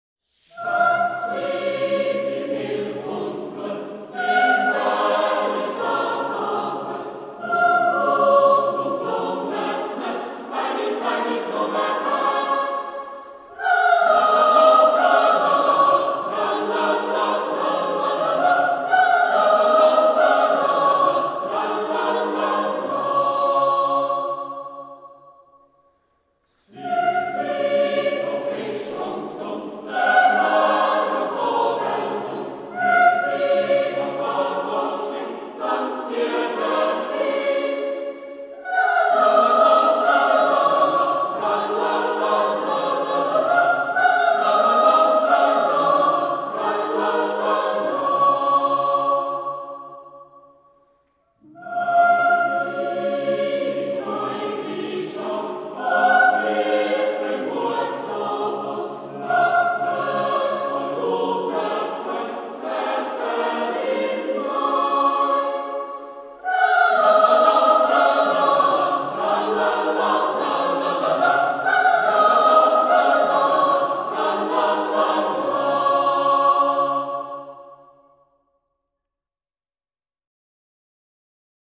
Zurich Boys’ Choir – The most beautiful Swiss folk songs and tunes (Vol. 2)
Trad./Josef Estermann